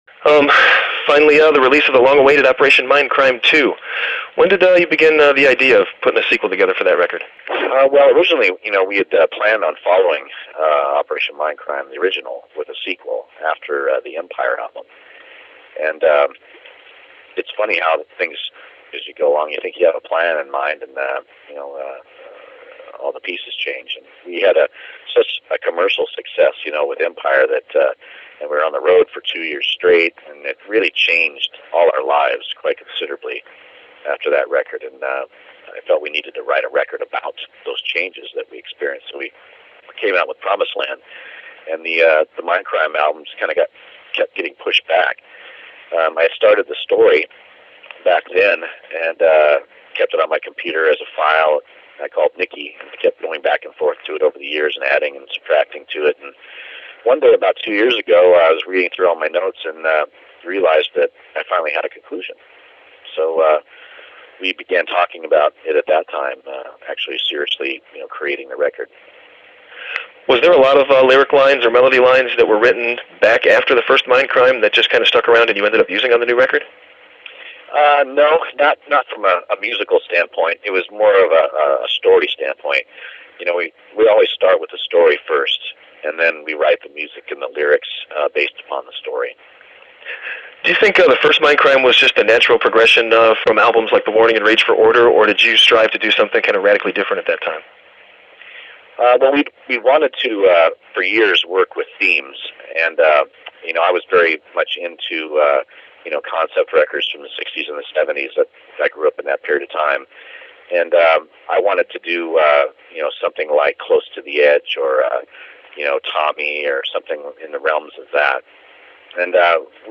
QUEENSRŸCHE: New GEOFF TATE Audio Interview Available For Download
geofftateInterview.mp3